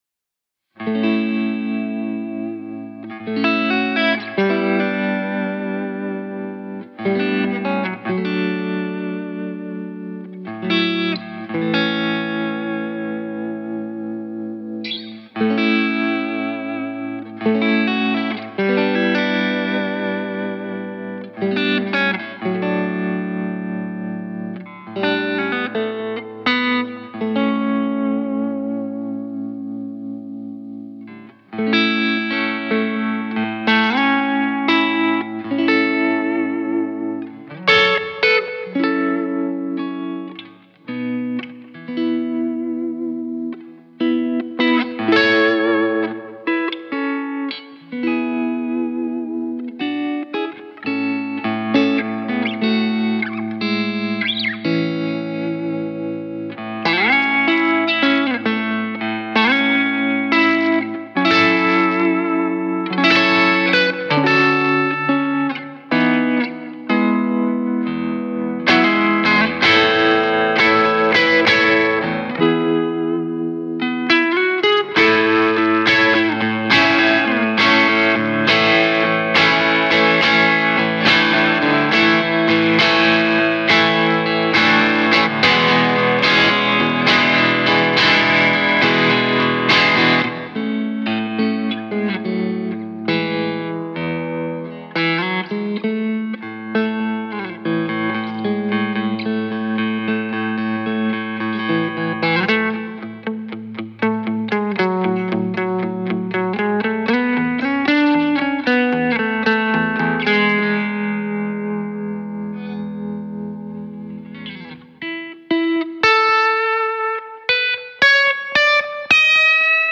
solo-guitar version